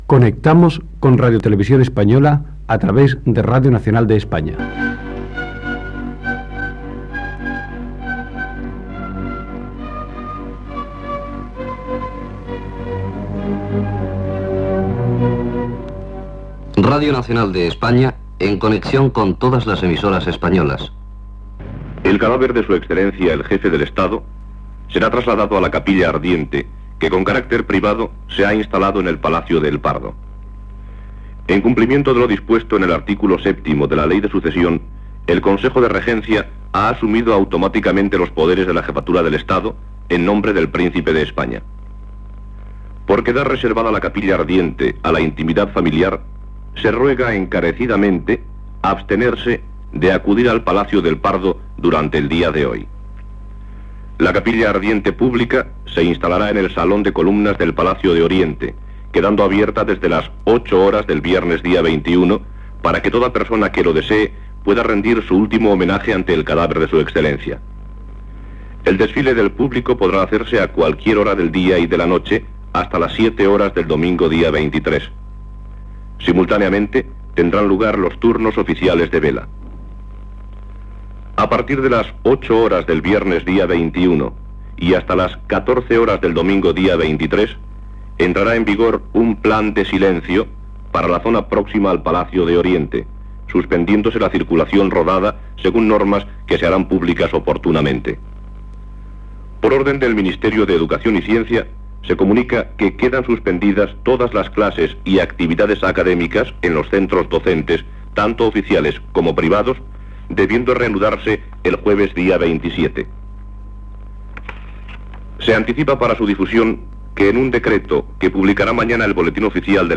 Programa especial amb motiu de la mort del cap d'Estat "generalísimo" Francisco Franco. Connexió de totes les emissores amb la programació especial de Radio Nacional de España.
Informatiu